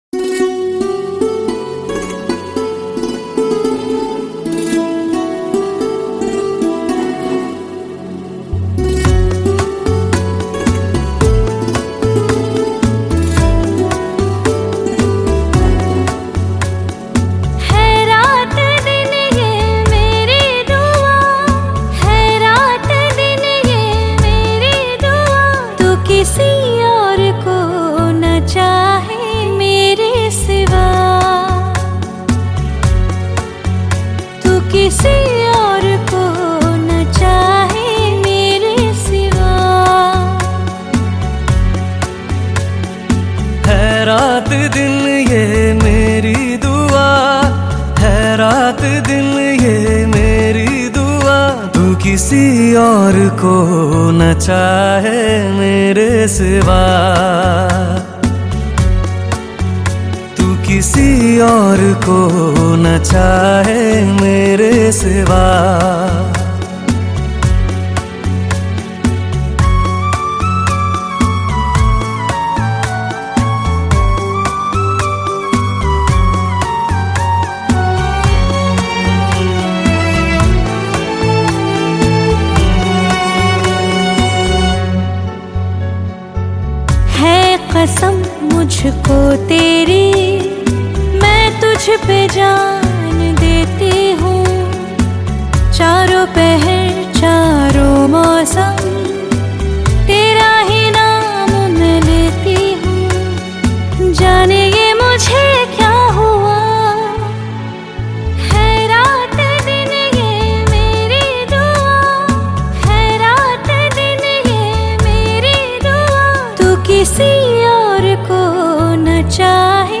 Hindi Pop Album Songs